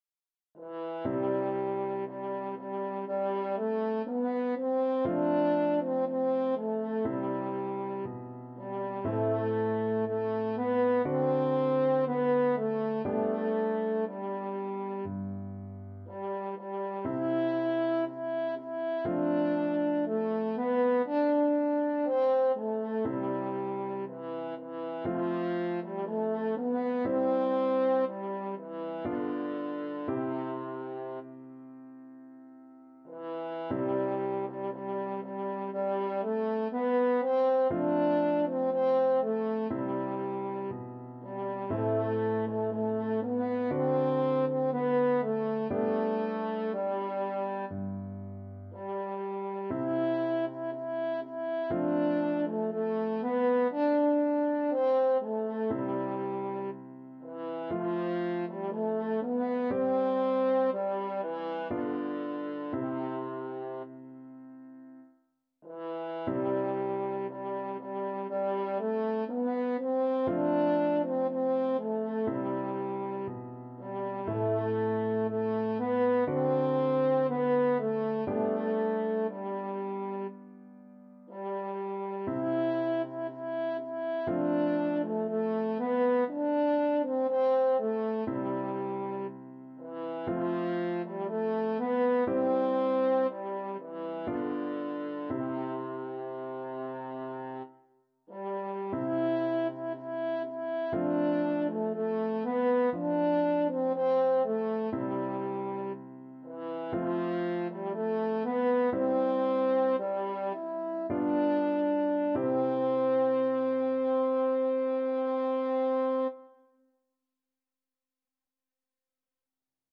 French Horn
C major (Sounding Pitch) G major (French Horn in F) (View more C major Music for French Horn )
~ = 100 Adagio
4/4 (View more 4/4 Music)
Classical (View more Classical French Horn Music)